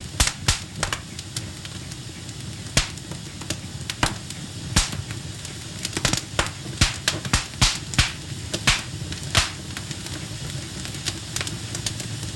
Campfire Small, Wood With Crackle and Flame